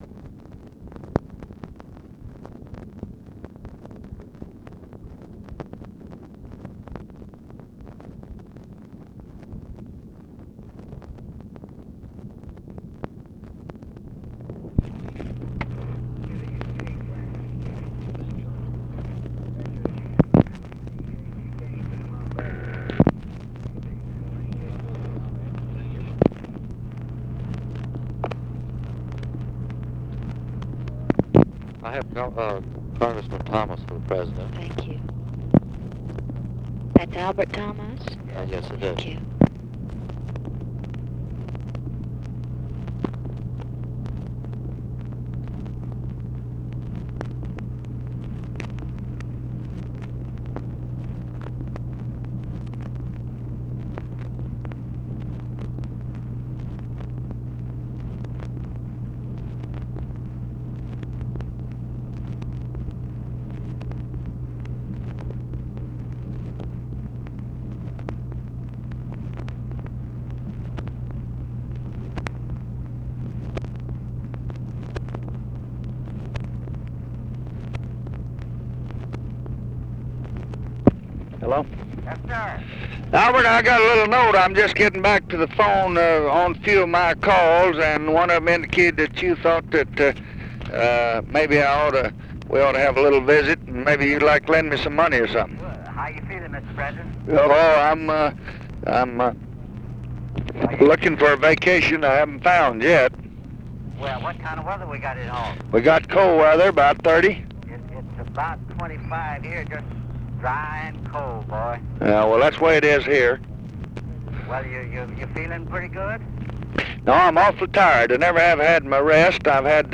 Conversation with ALBERT THOMAS, November 22, 1964
Secret White House Tapes